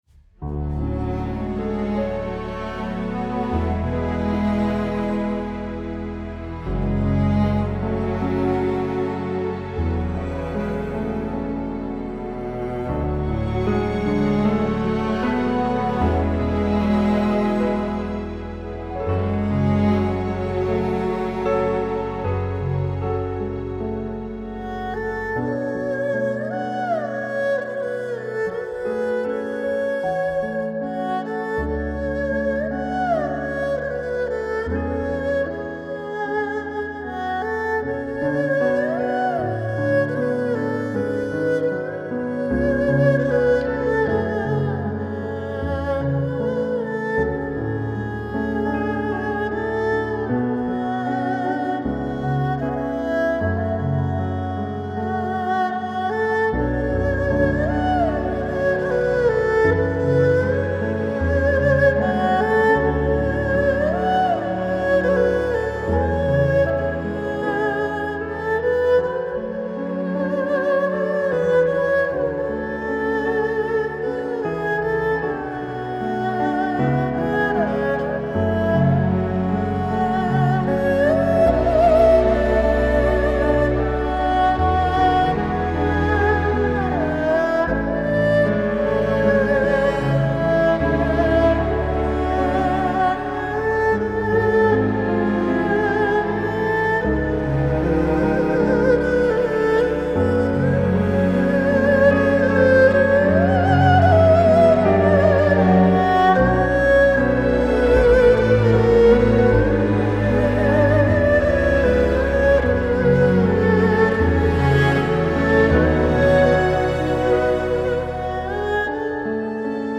سبک آرامش بخش , عصر جدید , موسیقی بی کلام